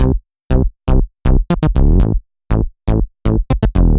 Index of /musicradar/french-house-chillout-samples/120bpm/Instruments
FHC_MunchBass_120-C.wav